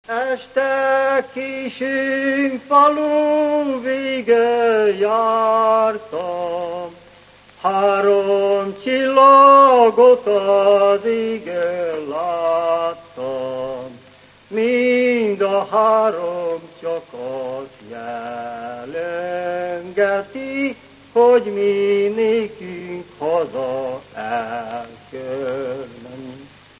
Dunántúl - Somogy vm. - Nemespátró
Műfaj: Lakodalmas
Stílus: 6. Duda-kanász mulattató stílus
Kadencia: 4 (1) X 1